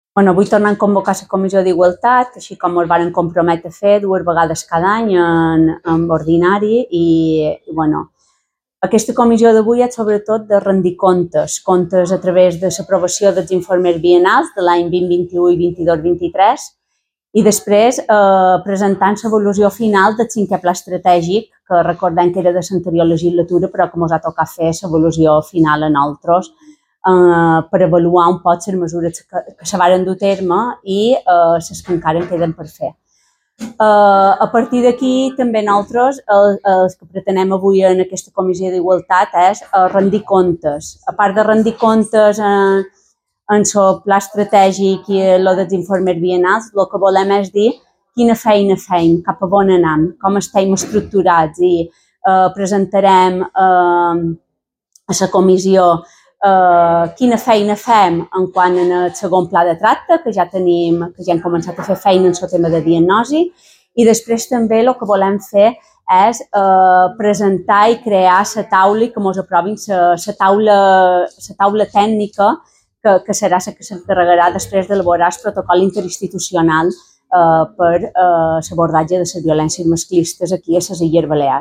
Declaracions de la directora de l'IBDONA, Catalina Salom